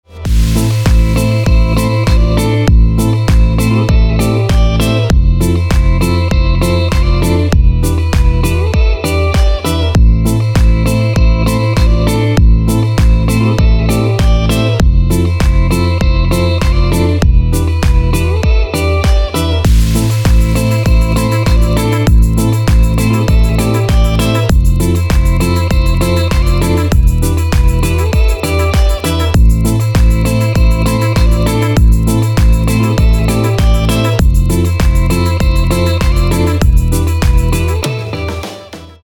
• Качество: 320, Stereo
красивые
deep house
dance
Electronic
без слов